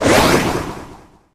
他的语音主要为一些嘲讽。
使用终极技能时